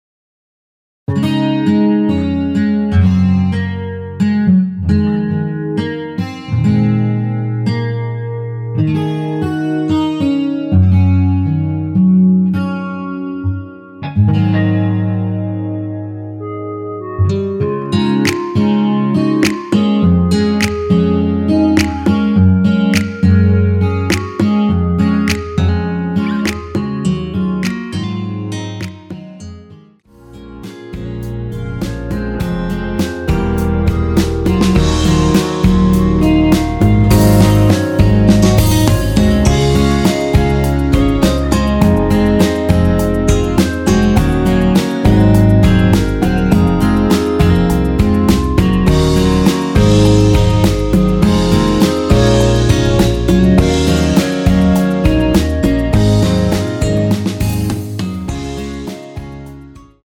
엔딩이 페이드 아웃이라 라이브 하시기 좋게 엔딩을 만들어 놓았습니다.
원키에서(-2)내린 멜로디 포함된 MR입니다.
Db
앞부분30초, 뒷부분30초씩 편집해서 올려 드리고 있습니다.
중간에 음이 끈어지고 다시 나오는 이유는